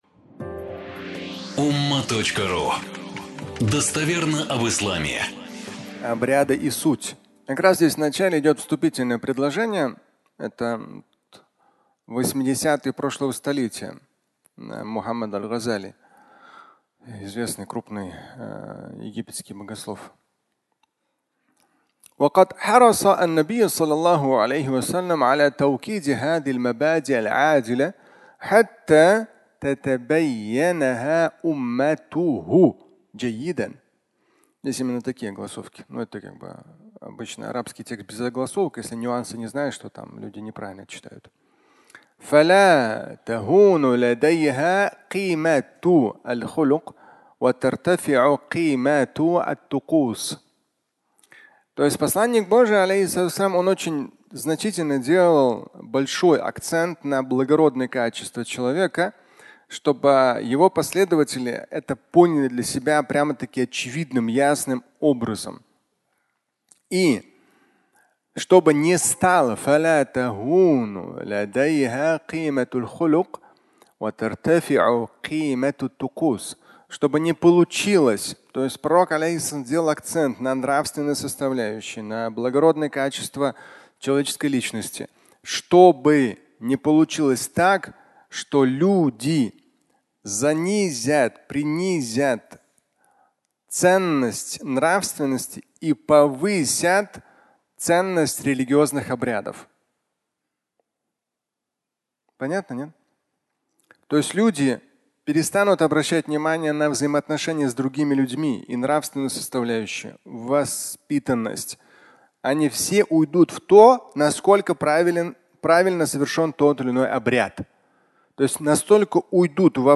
Обряды и суть (аудиолекция)